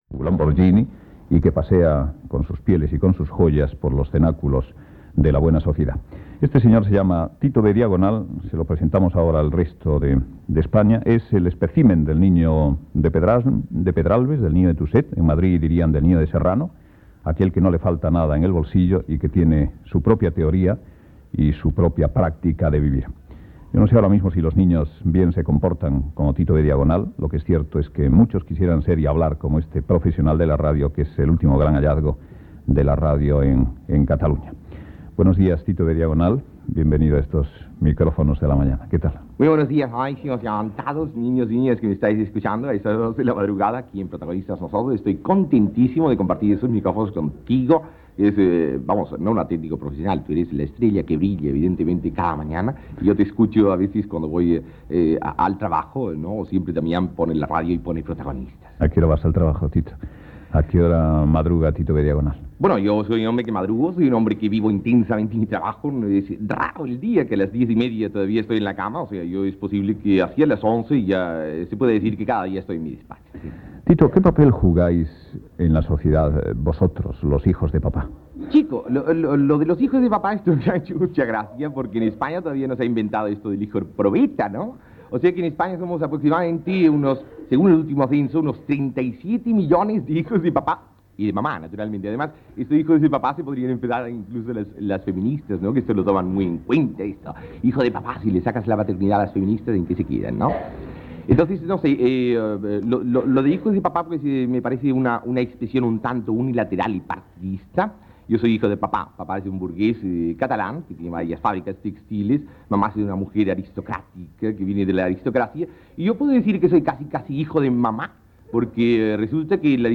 Presentació del col·laborador Tito B. Diagonal i diàleg sobre les seves activitats i opinions personals Gènere radiofònic Info-entreteniment Presentador/a Olmo, Luis del